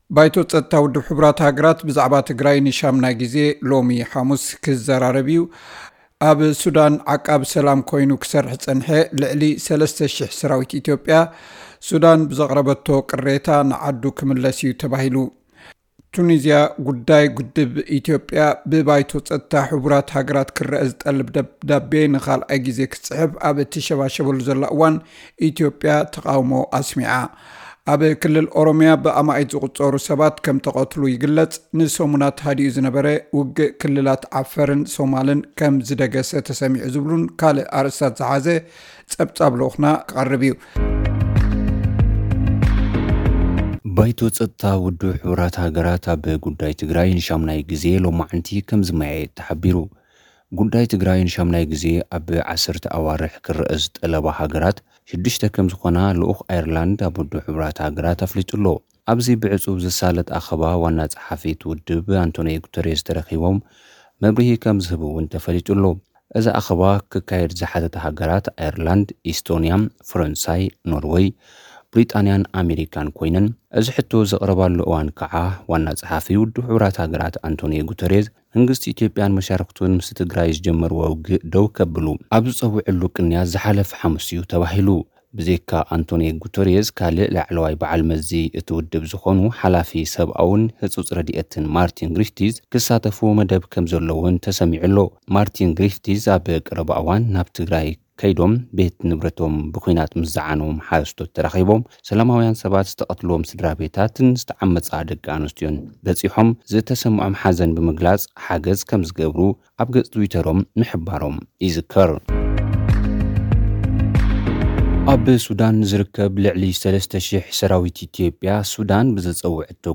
ባይቶ ጸጥታ ውድብ ሕቡራት ሃገራት ብዛዕባ ትግራይ ን8ይ ጊዜ ሎሚ ሓሙስ ክዘራረብ እዩ። (ጸብጻብ)